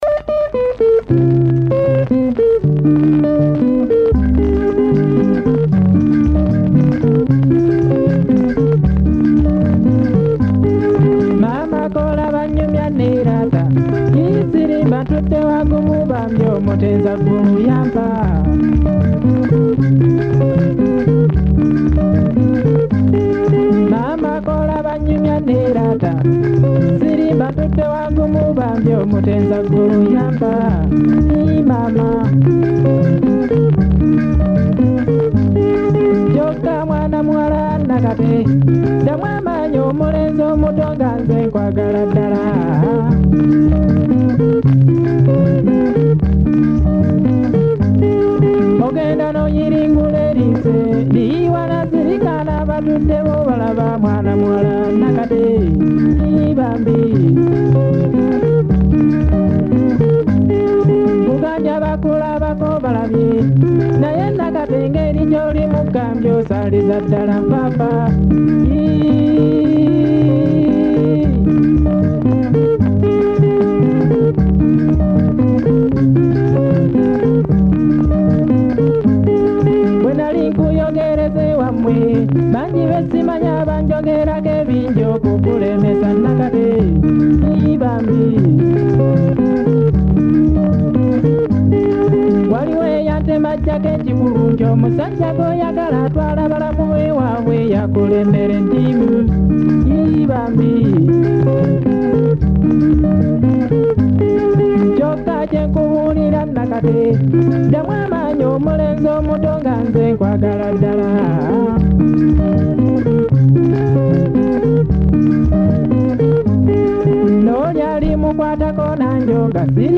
Genre: Kadongo Kamu